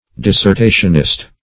Dissertationist \Dis`ser*ta"tion*ist\, n. A writer of dissertations.